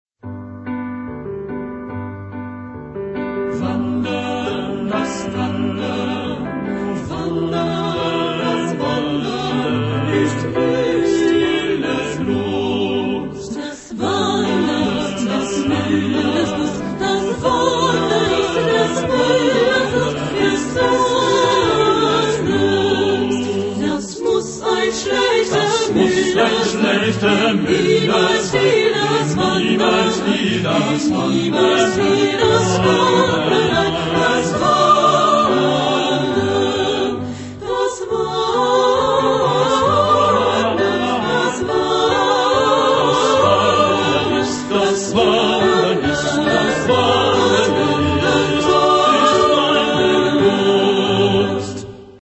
Genre-Style-Forme : Profane ; Variété ; Pop
Caractère de la pièce : énergique ; joyeux
Type de choeur : SATB  (4 voix mixtes )
Instruments : Piano (1)
Tonalité : sol majeur